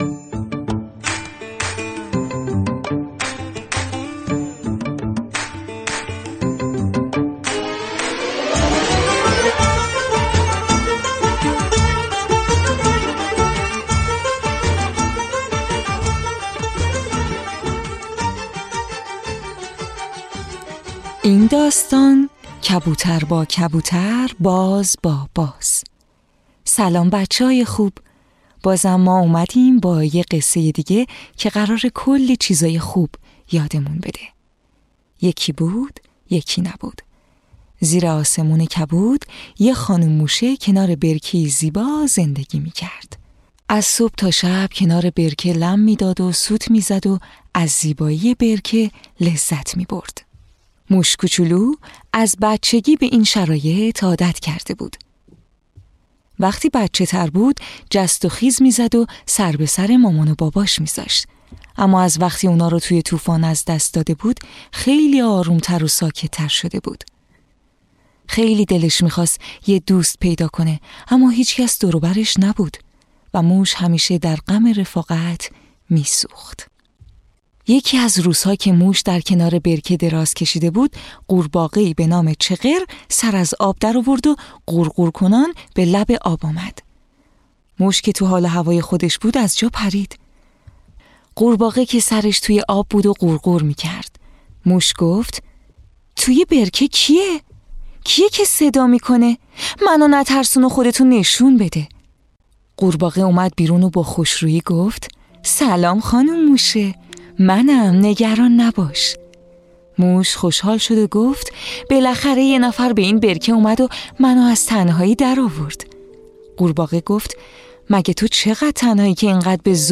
قصه های کودکانه صوتی - این داستان: کبوتر با کبوتر، باز با باز
تهیه شده در استودیو نت به نت